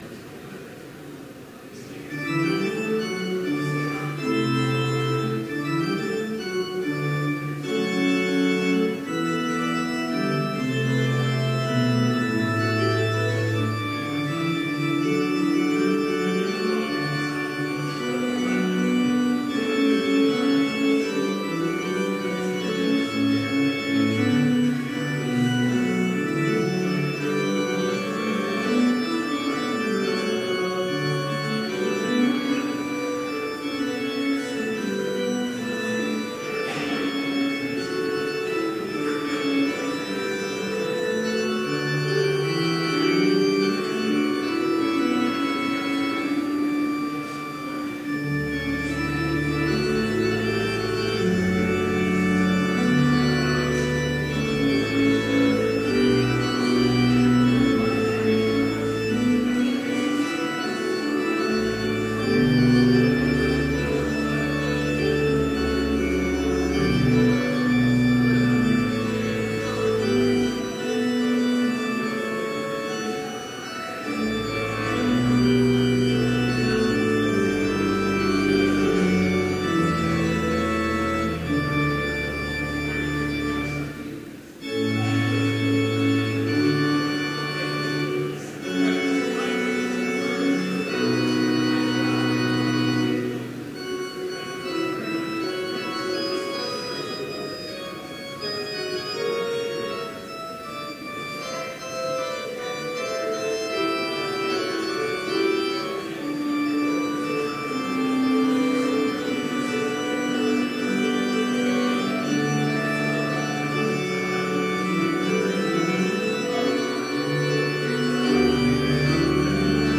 Complete service audio for Chapel - February 2, 2017